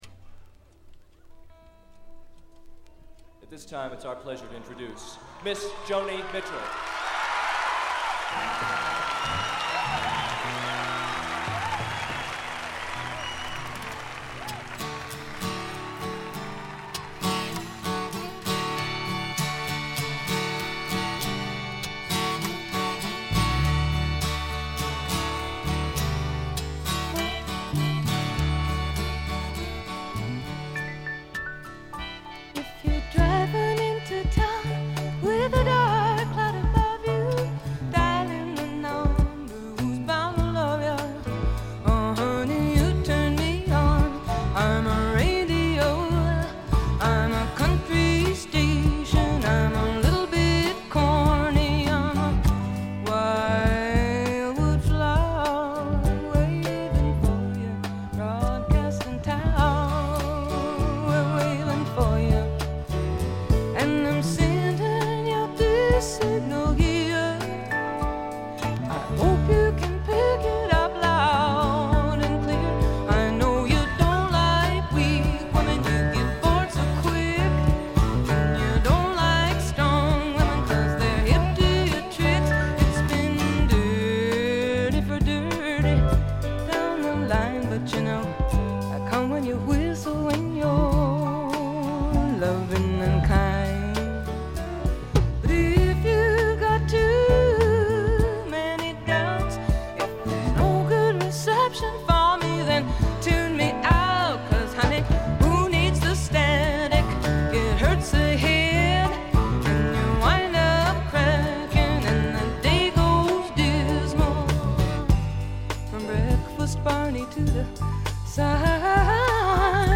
静音部で微差なチリプチが少し聴かれる程度でほとんどノイズ感無し。
試聴曲は現品からの取り込み音源です。